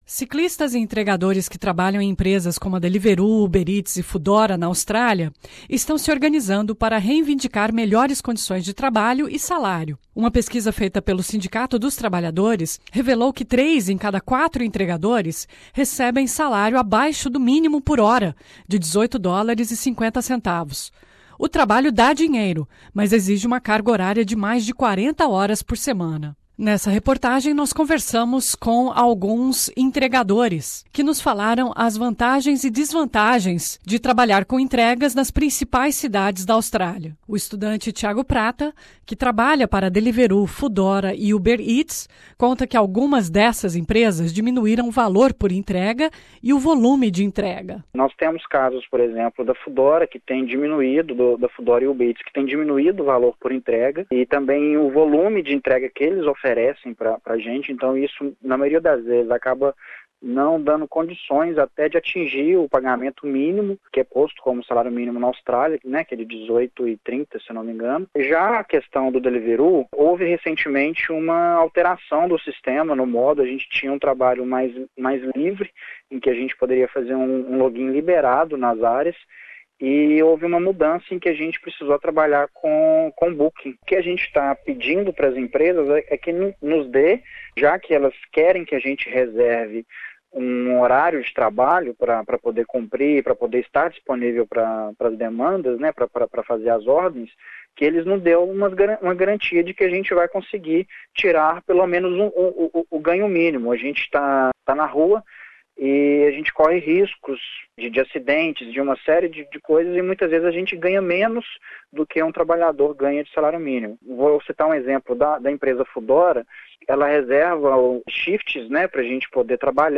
Ciclistas, ‘riders’ e entregadores que trabalham para empresas como a Deliveroo, Ubereats e Foodora estão organizando um protesto para reivindicar melhores salários - piso mínimo de AU$ 18,50 por hora - e condições de trabalho. Nessa reportagem especial, riders brasileiros falam sobre suas reivindicações, acidentes que tiveram no trabalho, a evolução da indústria de delivery, competição entre ciclistas e as vantagens e desvantagens desse tipo de trabalho nas principais cidades da Austrália.